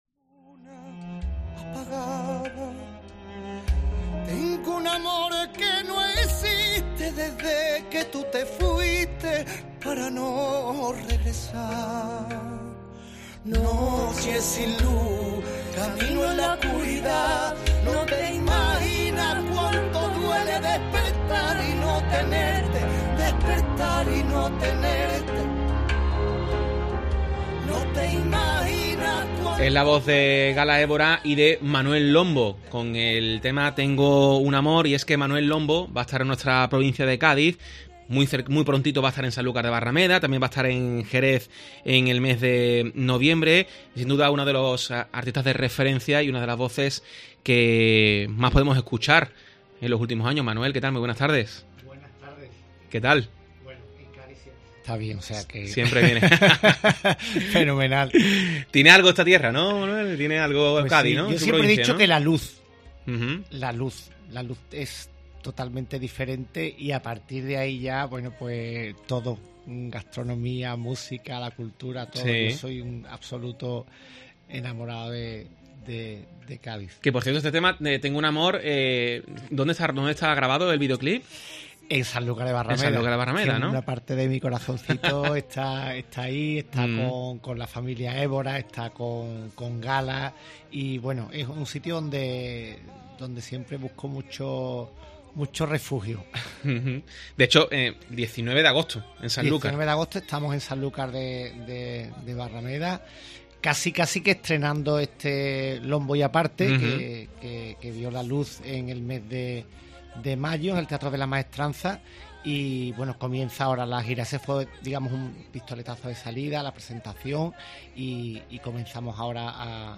ESCUCHA LA ENTREVISTA A MANUEL LOMBO EN COPE